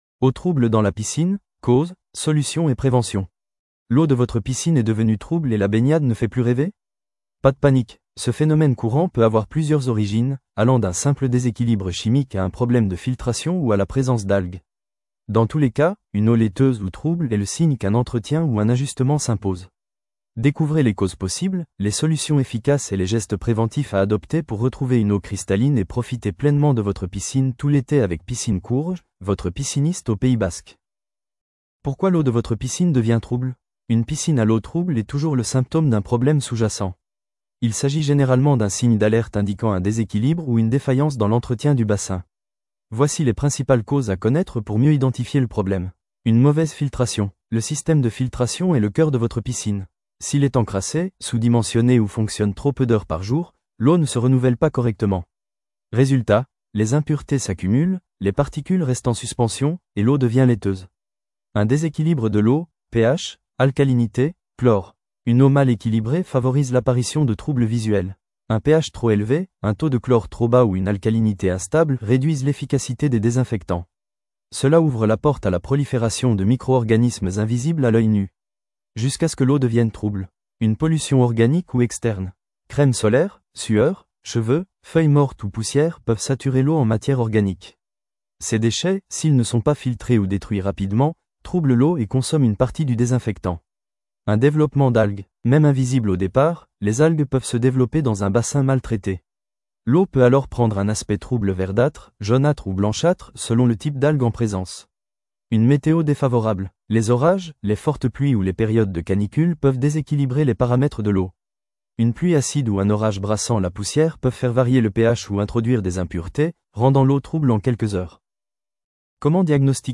Retranscription IA :